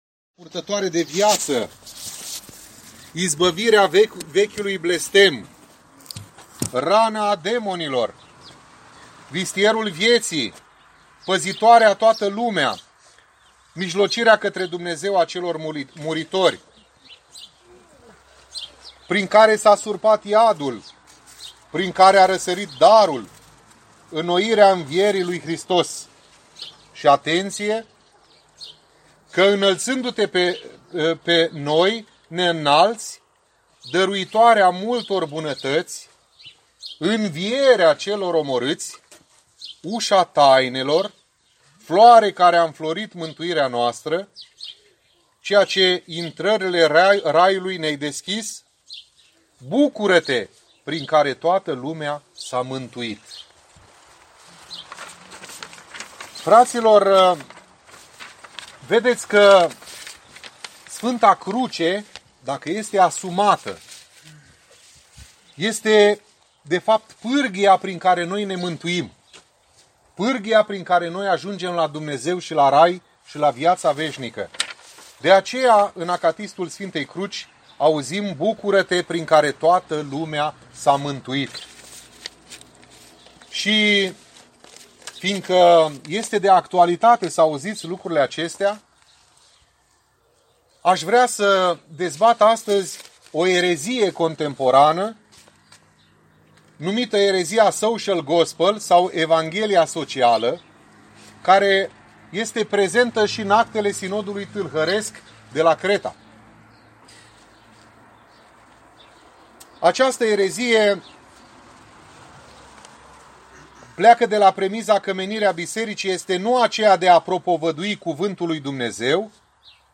Predici